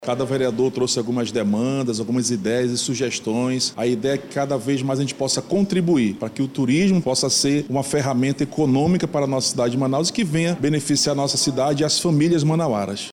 Integrante da Comissão de Turismo da CMM, o vereador Raiff Matos, do PL, destaca que o principal objetivo é traçar metas para fortalecer o setor como uma matriz econômica forte para a cidade.